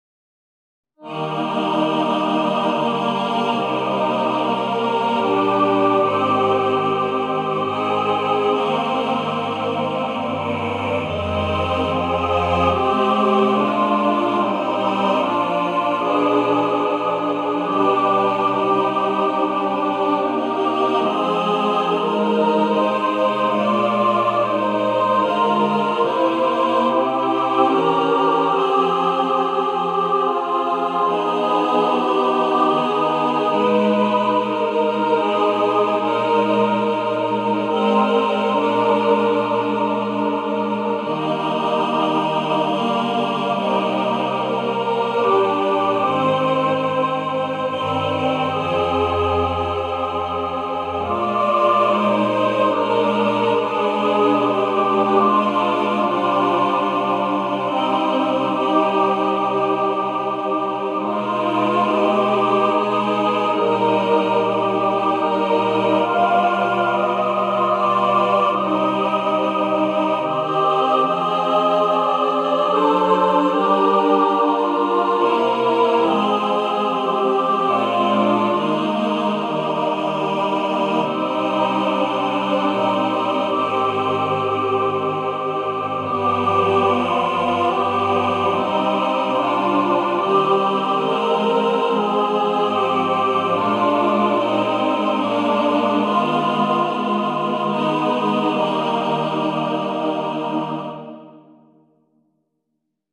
A beautiful, lilting, Christmas lullaby (hymn).